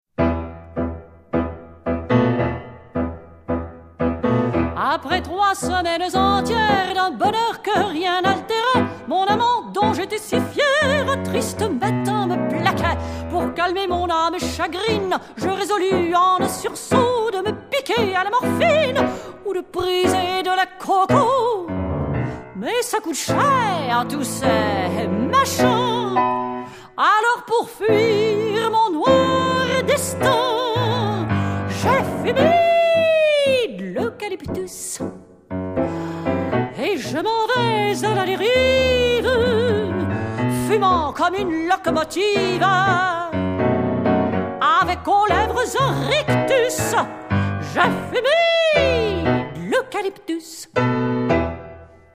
chant
piano
elle passe d'un climat musical à l'autre avec souplesse et élégance.